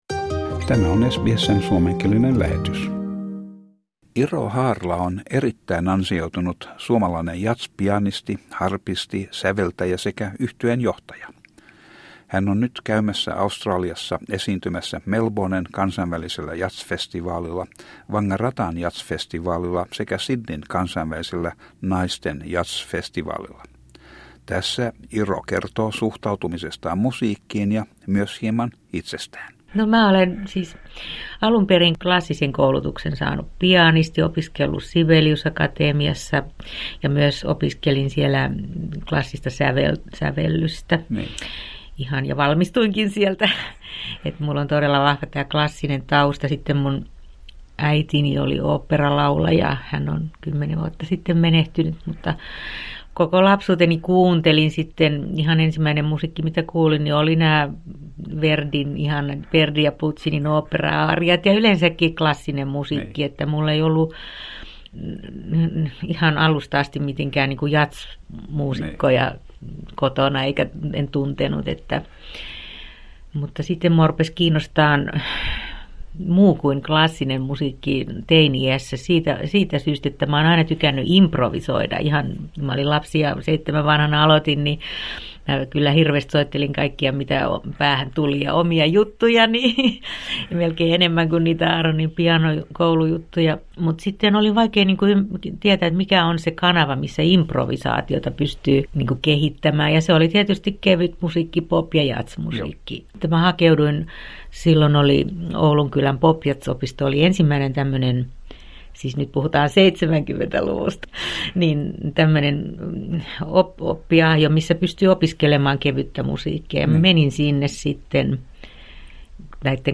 Iro Haarla on johtava jazzmuusikko, säveltäjä ja orkesterinjohtaja. Hän kertoo tässä haastattelussa taustastaan ja musiikistaan.